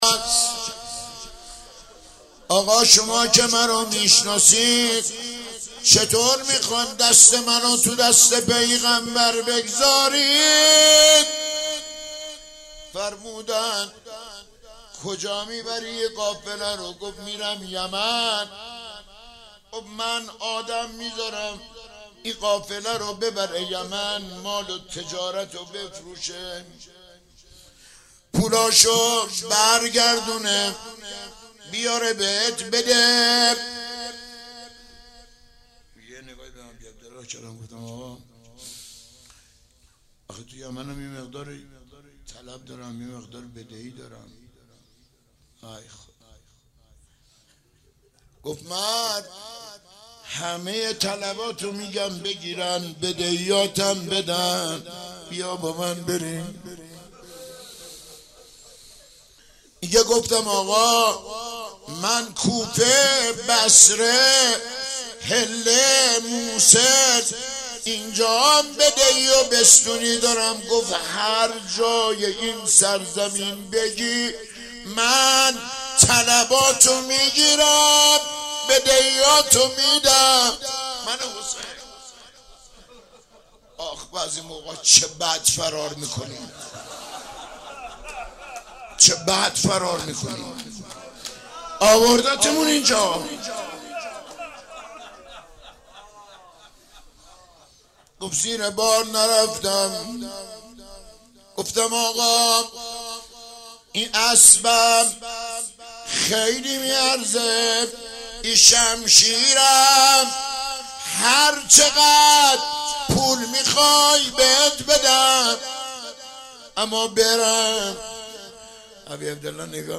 روضه حضرت اباعبدالله الحسین